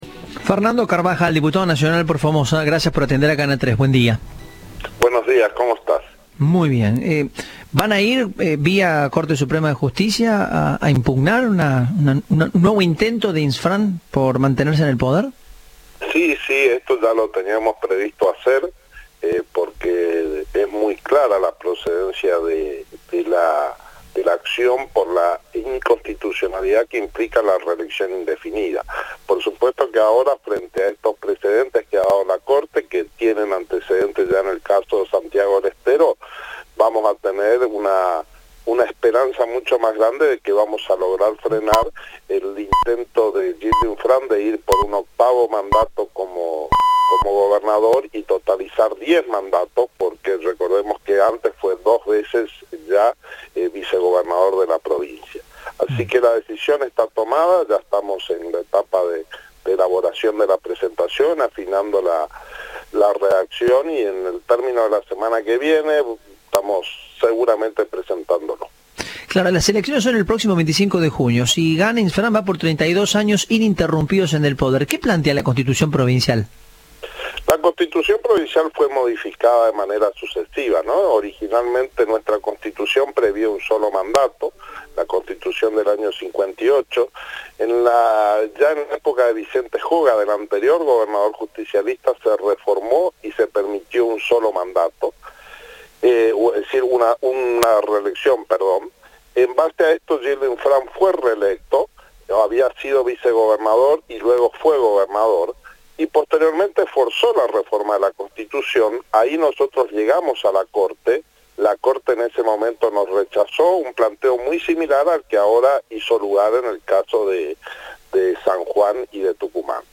Fernando Carbajal, diputado nacional y precandidato en la provincia por Juntos por el Cambio, dijo que es "inconstitucional la reelección indefinida" del actual gobernador.
Entrevista